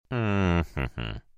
Звук неуверенного отрицания
• Категория: Отрицание - нет
• Качество: Высокое